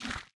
Minecraft Version Minecraft Version latest Latest Release | Latest Snapshot latest / assets / minecraft / sounds / block / sculk / spread4.ogg Compare With Compare With Latest Release | Latest Snapshot
spread4.ogg